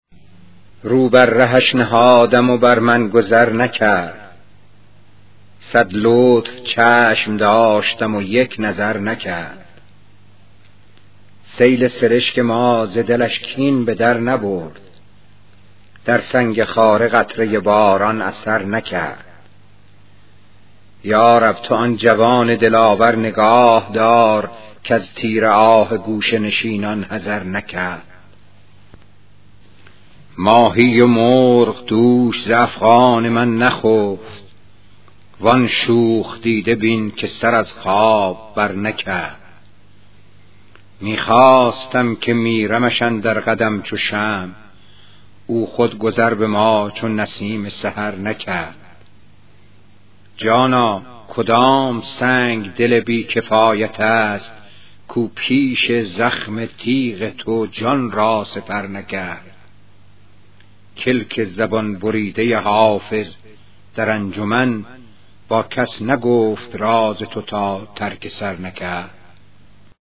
پخش صوتی غزل